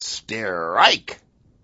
wack_strrrike.wav